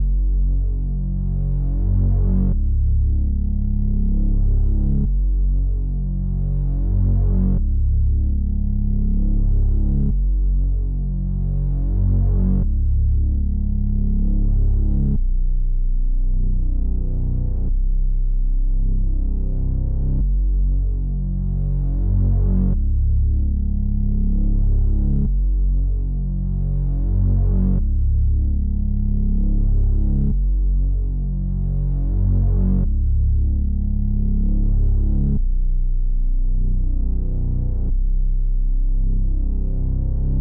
低音5层，有空气感的合唱3层
描述：F小调的低音5号。与空灵的合唱团3分层
Tag: 95 bpm Trap Loops Bass Synth Loops 6.80 MB wav Key : Fm Ableton Live